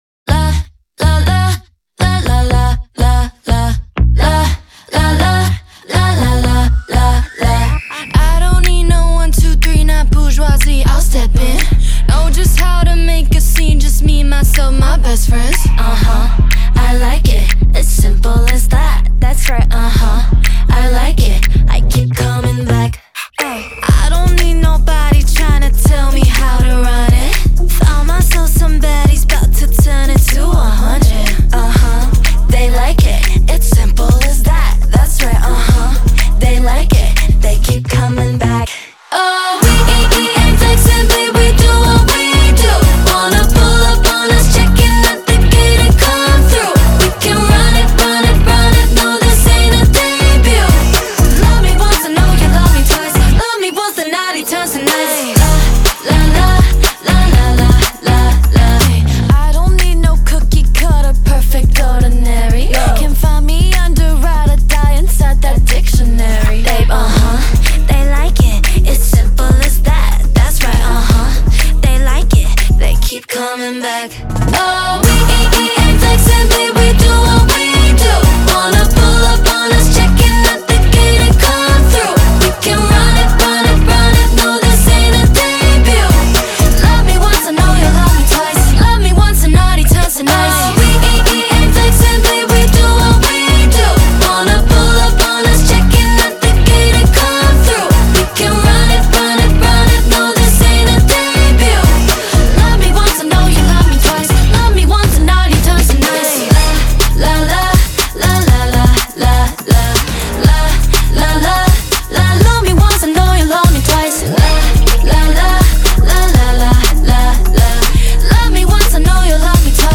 BPM122-122
K-Pop song for StepMania, ITGmania, Project Outfox
Full Length Song (not arcade length cut)